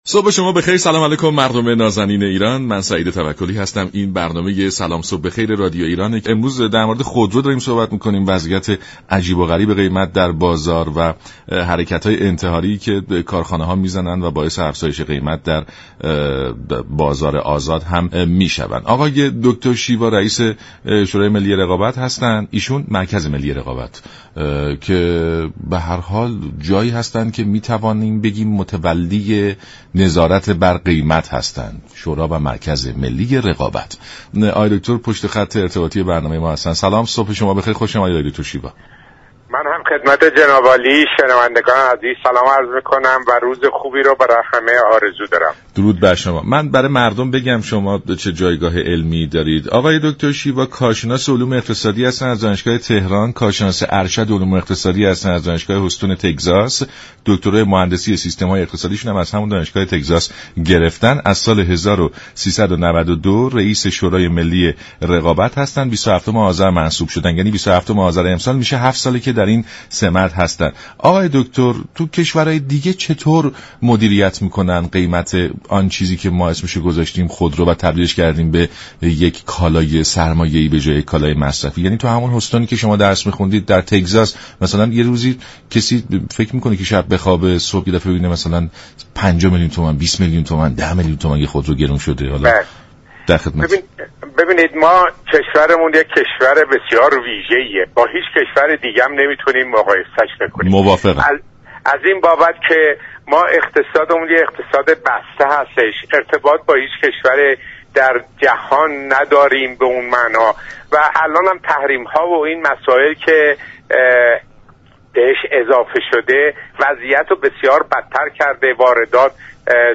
رییس شورای ملی رقابت در برنامه سلام صبح بخیر رادیو ایران گفت: تحریم ها علیه ایران میزان واردات را به صفر رسانده است.